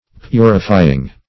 Purifying (p[=u]"r[i^]*f[imac]"[i^]ng).]